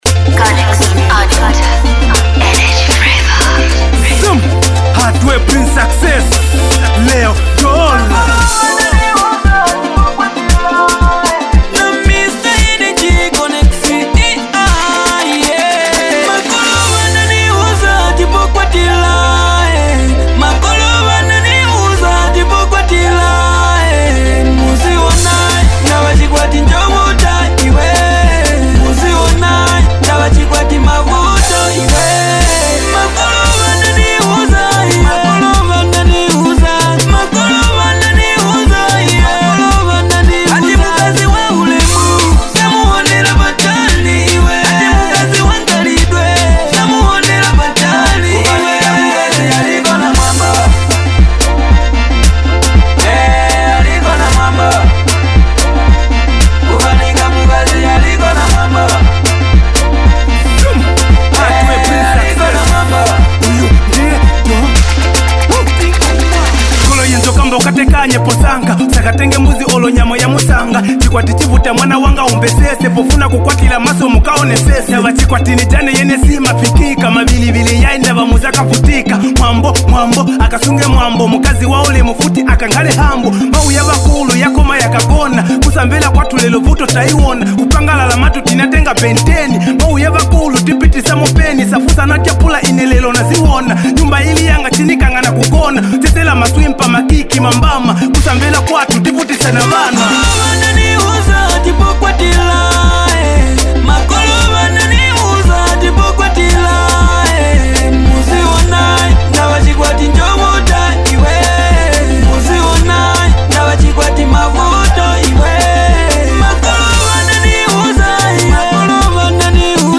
With smooth vocals and deep emotion
a perfect blend of passion and melody